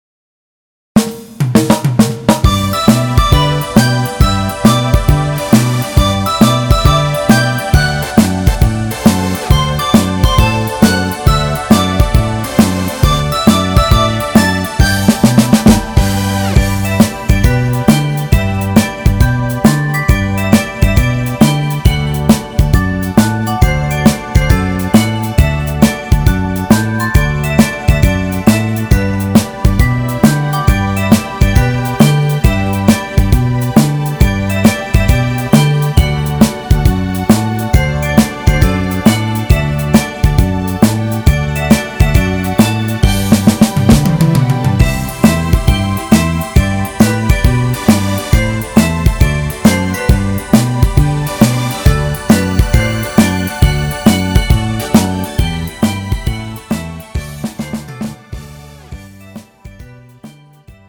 음정 G 키
장르 가요 구분 Pro MR